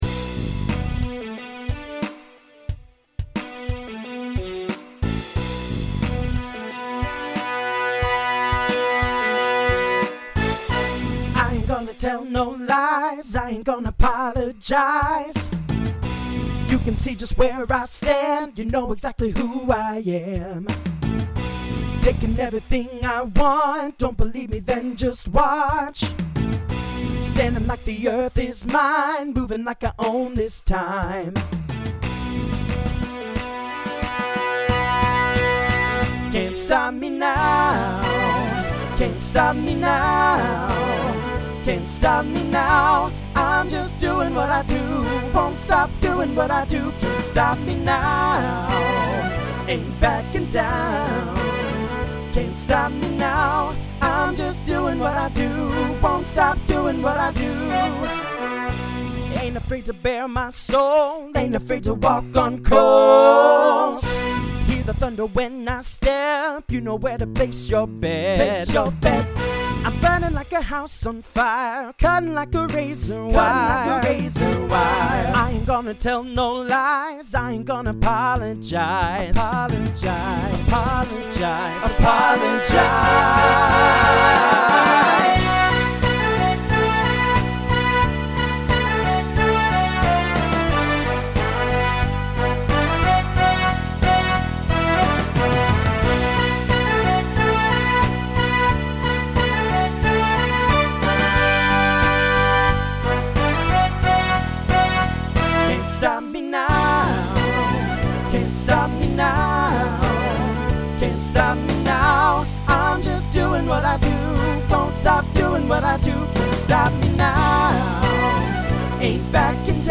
Voicing TTB Instrumental combo Genre Rock
Mid-tempo